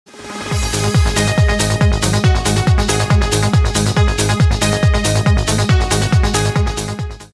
• Качество: 128, Stereo
громкие
Electronic
без слов
Trance